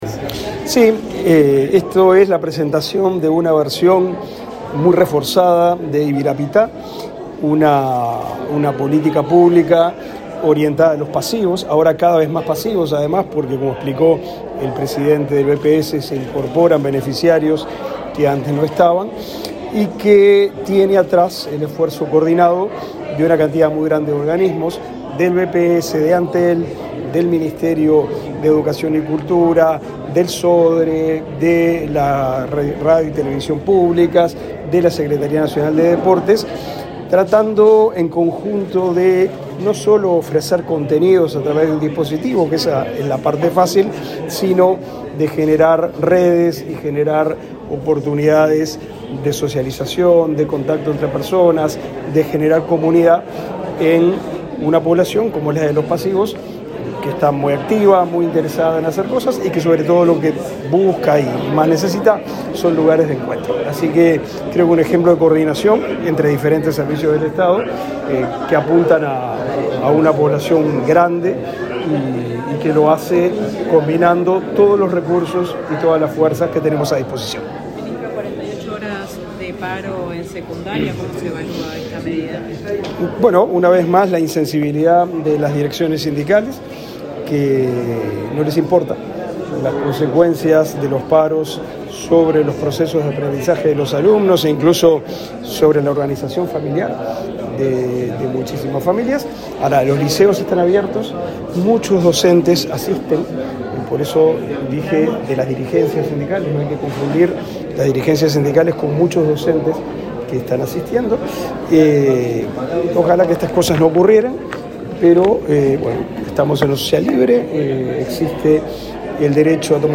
Declaraciones del ministro de Educación y Cultura, Pablo da Silveira
El ministro Pablo da Silveira, dialogó con la prensa luego del acto.